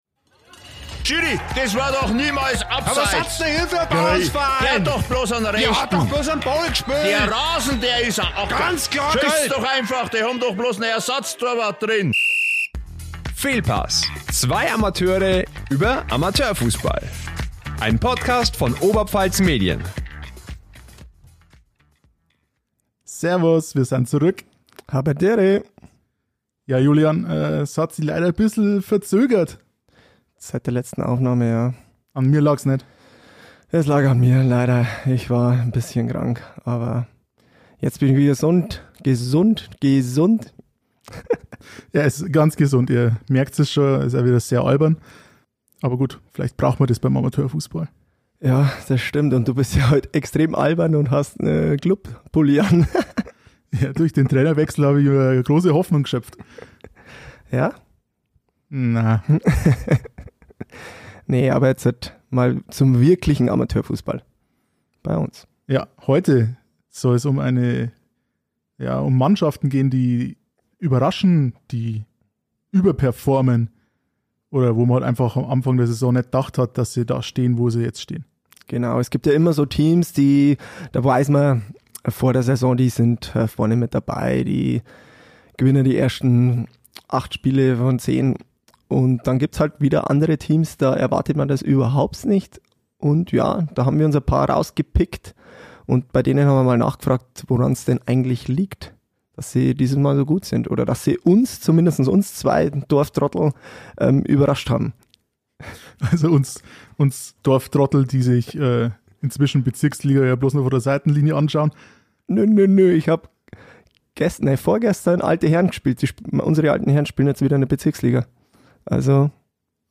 In der neuen Folge "Fehlpass" geht es um die Mannschaften, die uns überrascht haben oder die wir nicht dort erwartet haben, wo sie im Moment stehen. Mit Stimmen aus Vohenstrauß, Vorbach, Kohlberg, Michaelpoppenricht, Etzelwang und Bärnau.